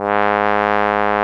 Index of /90_sSampleCDs/Roland LCDP12 Solo Brass/BRS_Trombone/BRS_Tenor Bone 2